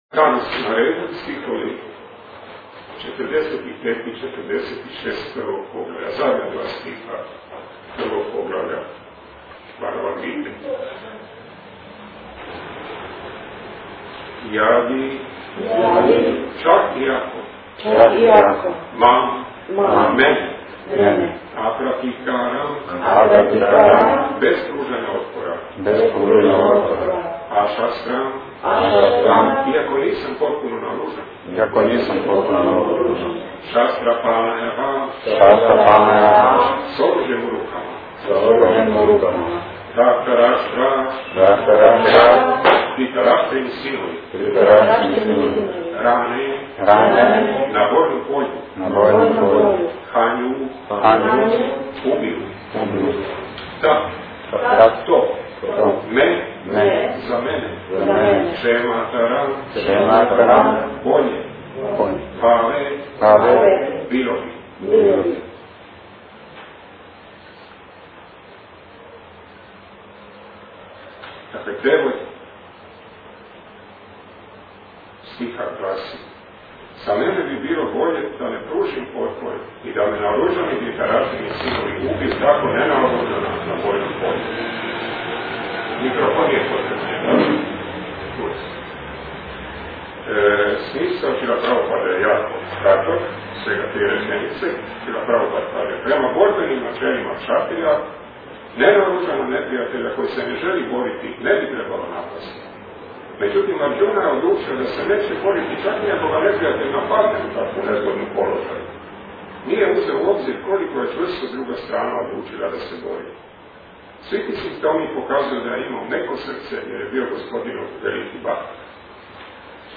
MP3 - Predavanja iz Rijeke